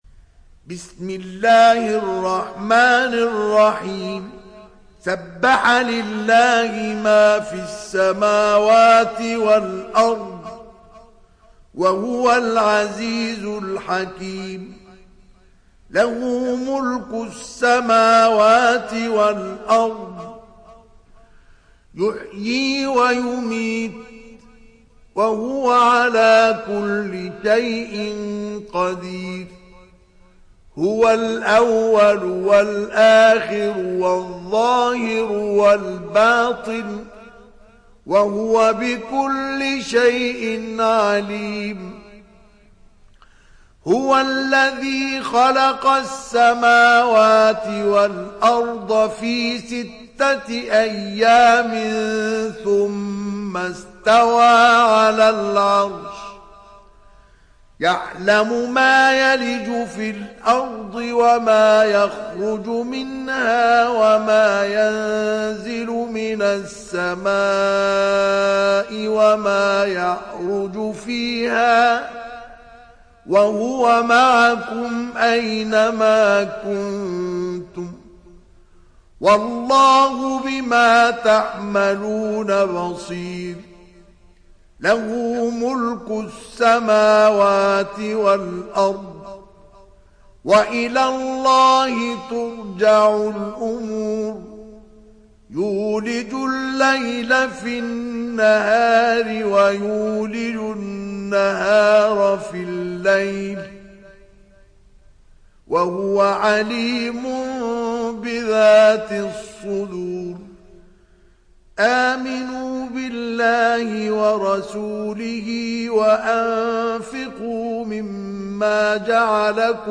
Sourate Al Hadid Télécharger mp3 Mustafa Ismail Riwayat Hafs an Assim, Téléchargez le Coran et écoutez les liens directs complets mp3
Télécharger Sourate Al Hadid Mustafa Ismail